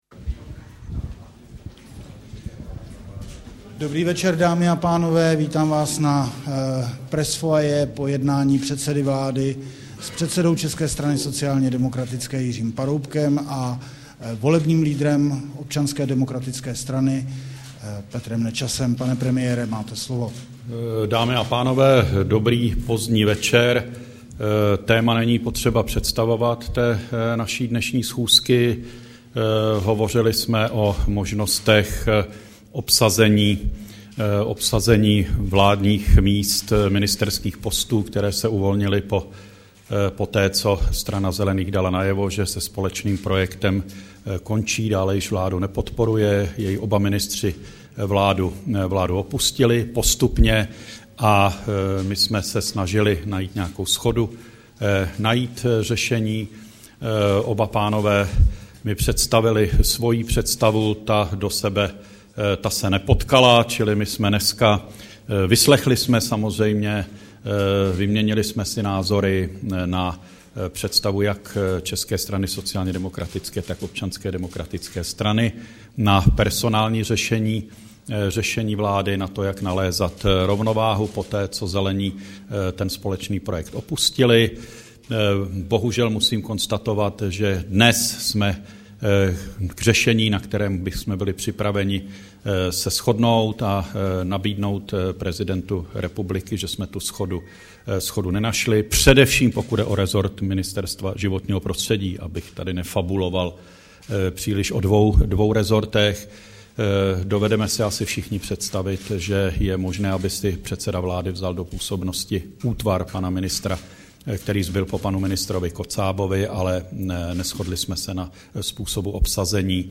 Tiskový brífink po jednání ČSSD a ODS, 1. dubna 2010